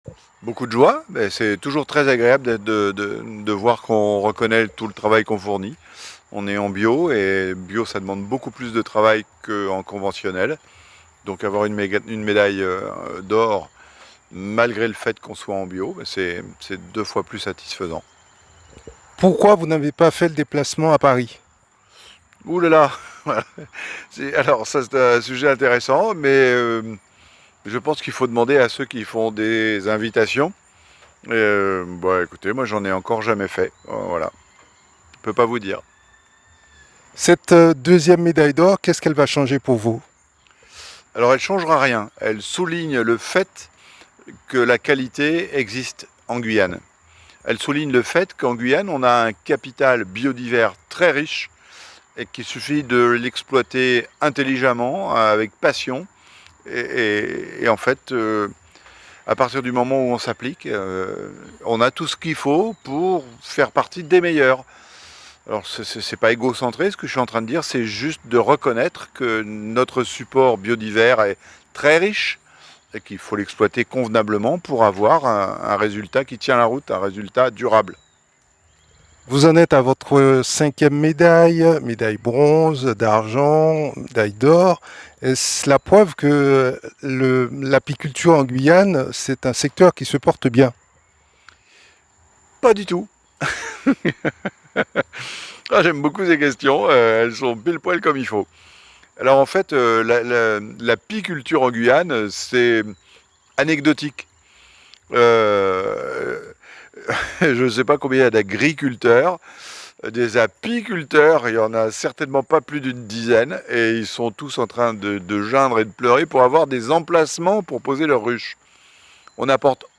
Il a accordé une interview exclusive à Radio Mayouri Campus.